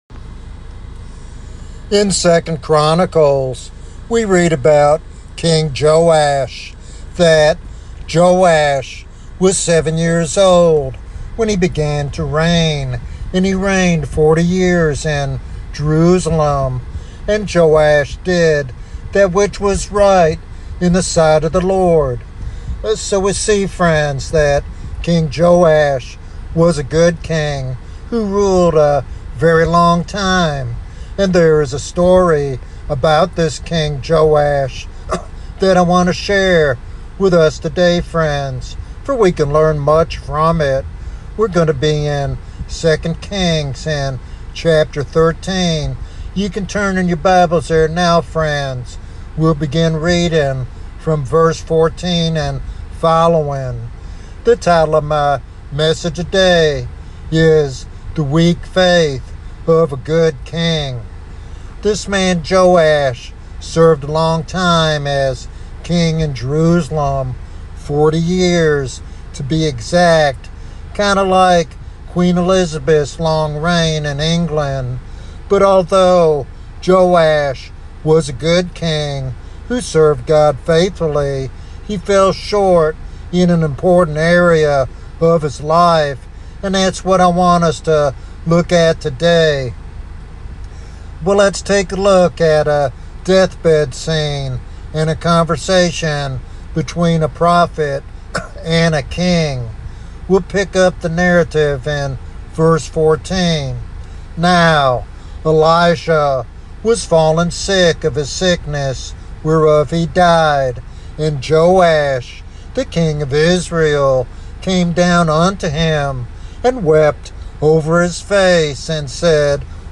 In this biographical sermon